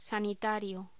Locución: Sanitario
Sonidos: Voz humana